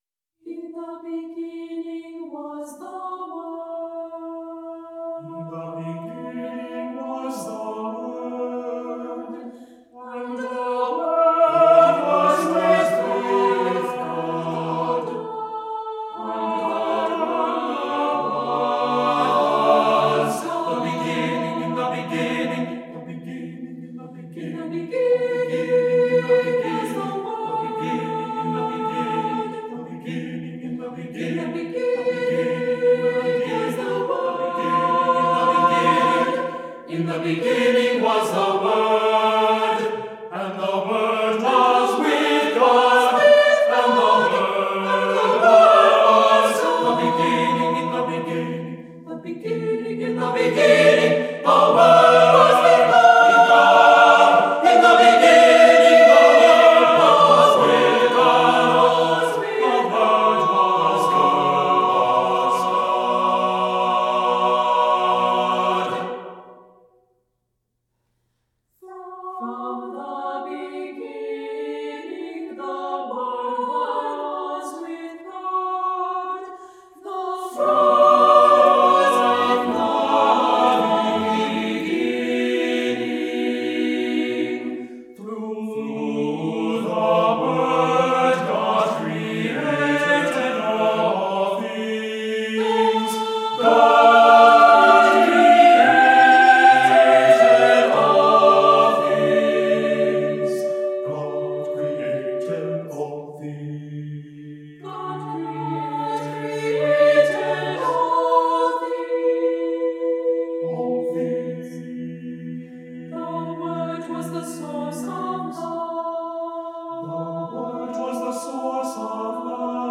Voicing: a cappella,SATB